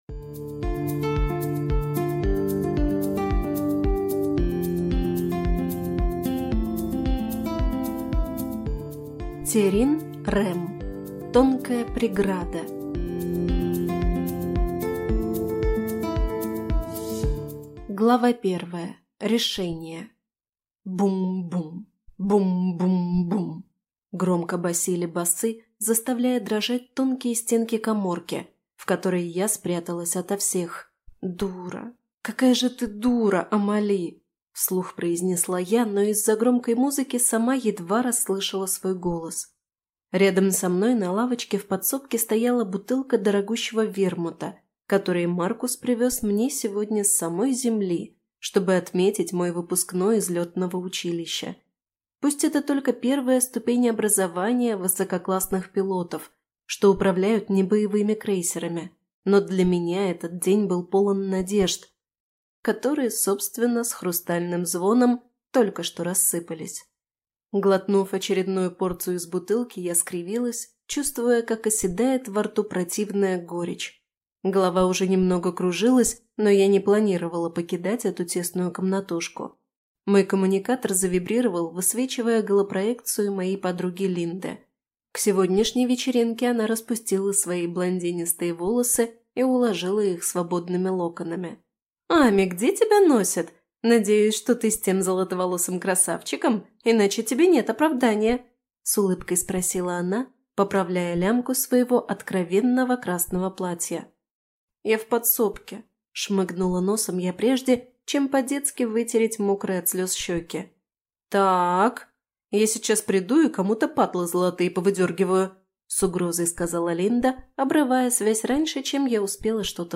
Аудиокнига Тонкая преграда | Библиотека аудиокниг
Прослушать и бесплатно скачать фрагмент аудиокниги